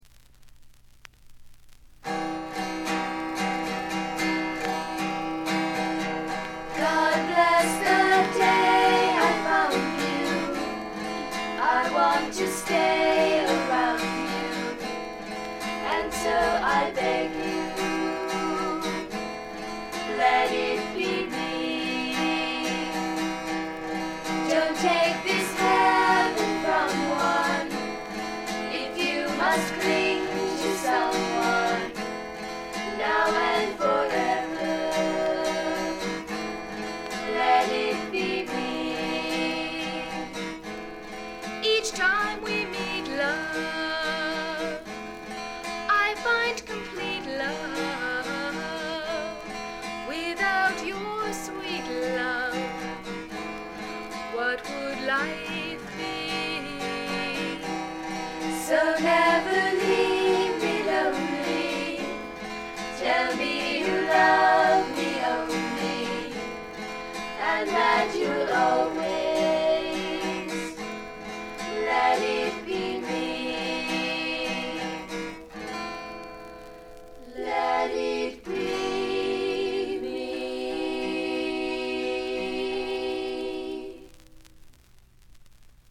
軽微なバックグラウンドノイズがずっと出ていますが鑑賞を妨げるようなものではありません。
有名曲中心のカヴァーをアコースティックギター1本の伴奏で清楚に歌います。
試聴曲は現品からの取り込み音源です。